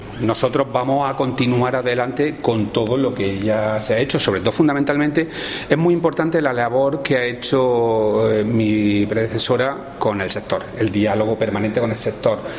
Declaraciones de Rodrigo Sánchez sobre la importancia del diálogo con el sector agrario